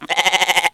1202_goat
bleat bleating countryside farm goat ringtone sonokids-omni sound effect free sound royalty free Sound Effects